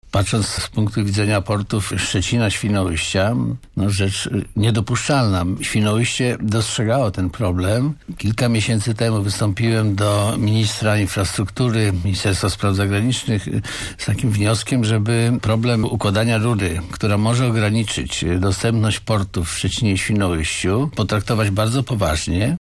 To szokująca informacja - tak na naszej antenie skomentował doniesienia gazety Nasz Dziennik Janusz Żmurkiewicz, prezydent Świnoujścia.